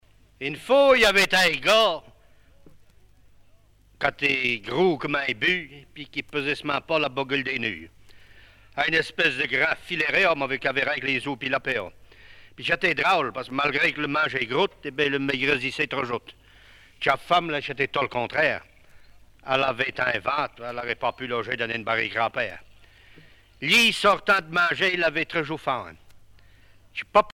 Genre sketch
Catégorie Récit